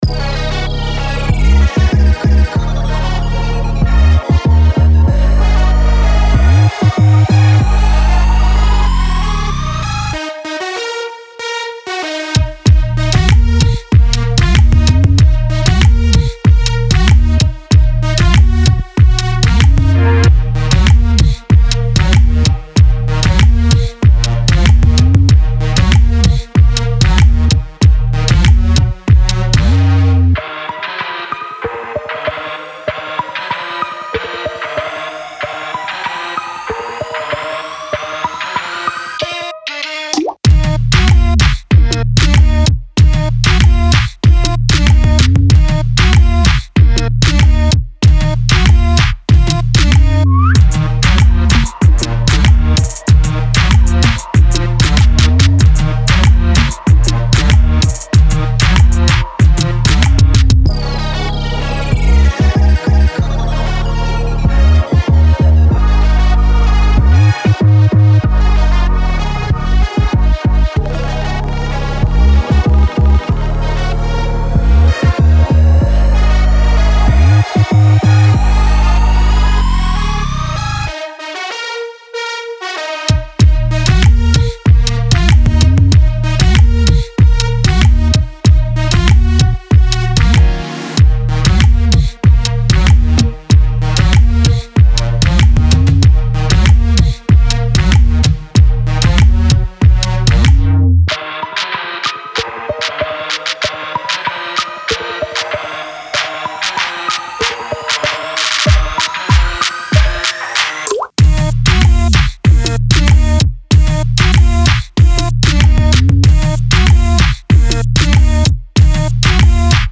EDM LATINO
Latin / Party / Pop / Banger